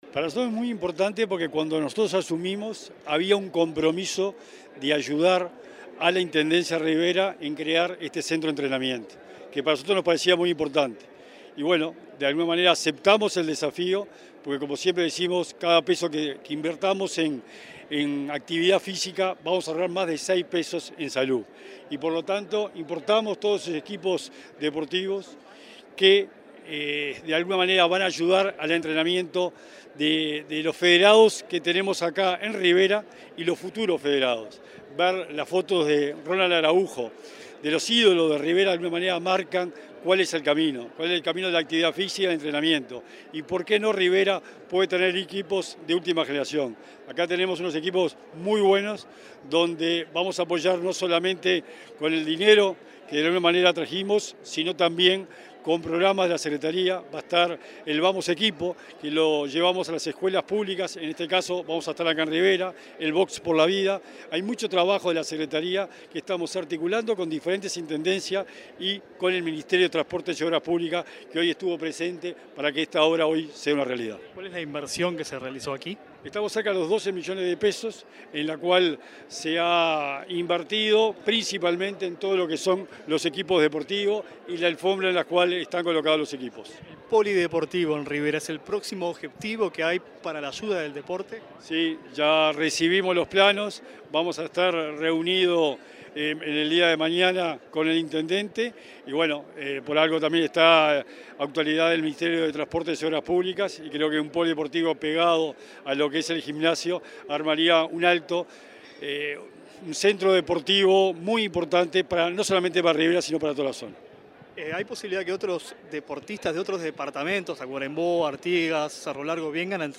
Declaraciones del secretario nacional del Deporte
Declaraciones del secretario nacional del Deporte 13/04/2023 Compartir Facebook X Copiar enlace WhatsApp LinkedIn El secretario nacional del Deporte, Sebastián Bauzá, dialogó en Rivera con Comunicación Presidencial, al inaugurar un centro de entrenamiento en ese departamento.